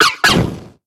Cri de Feunnec dans Pokémon X et Y.